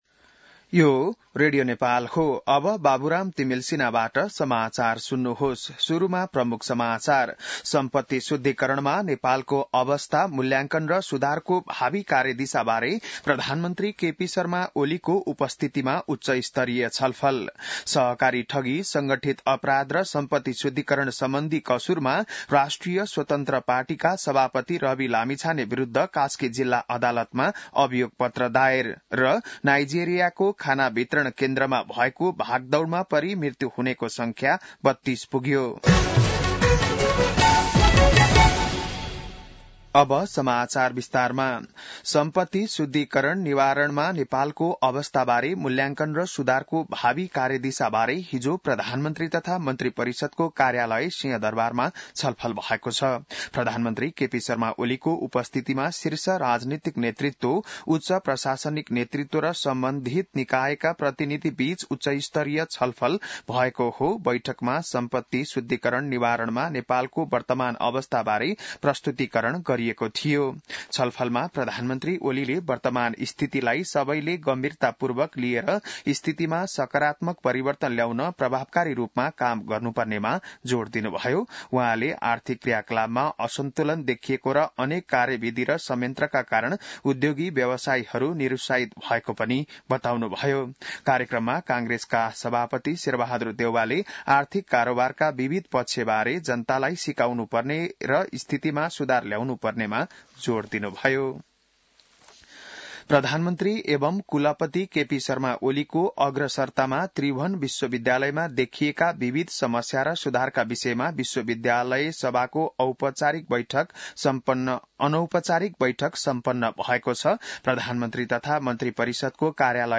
बिहान ९ बजेको नेपाली समाचार : ९ पुष , २०८१